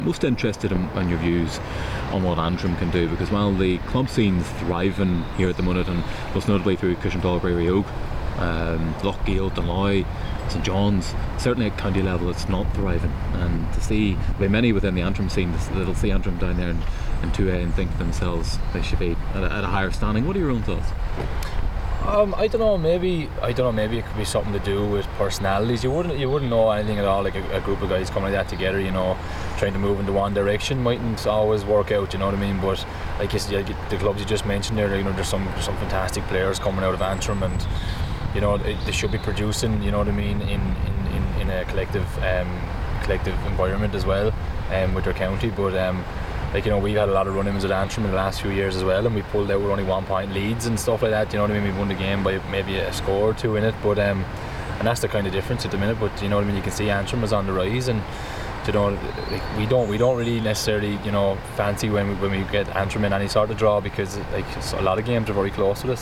Wexford's Lee Chin speaks to U105 Sport ahead of the start of the 2016 NHL.